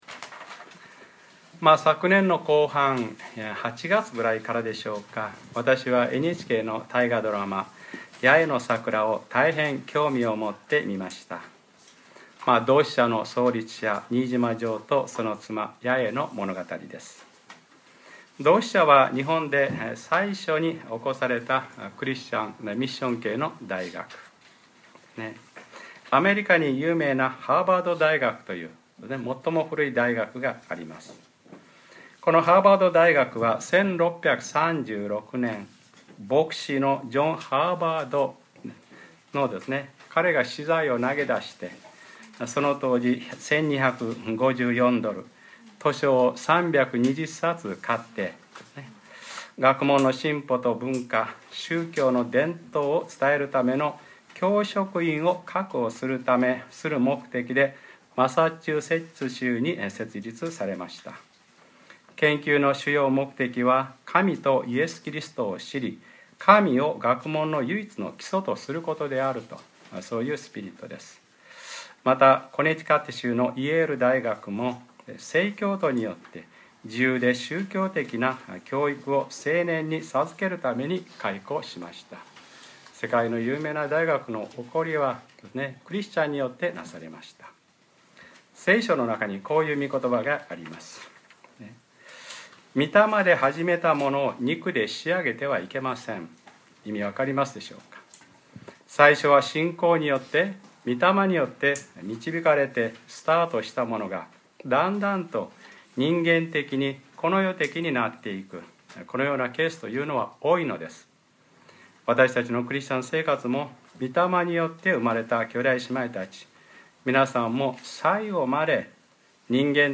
2014年1月26日（日）礼拝説教 『黙示録ｰ２６：大バビロンとそのさばき』 | クライストチャーチ久留米教会